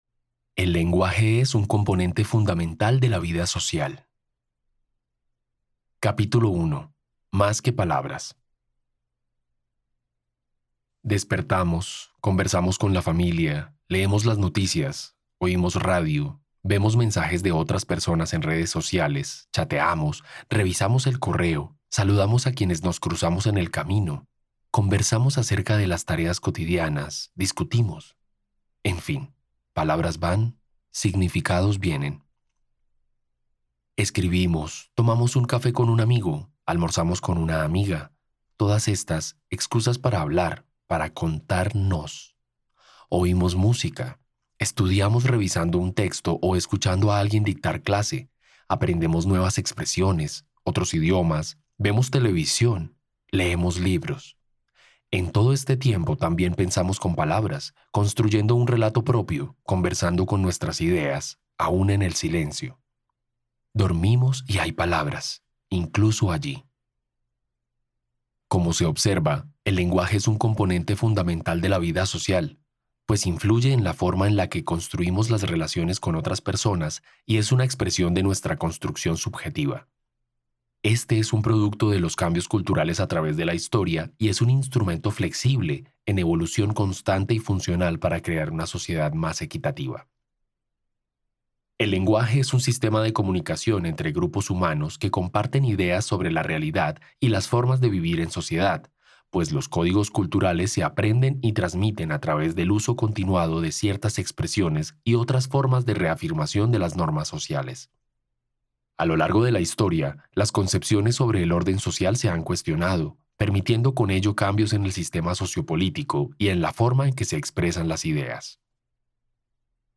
Audiolibro: Re-flexionar palabras. Lenguaje incluyente y no discriminatorio - Más que palabras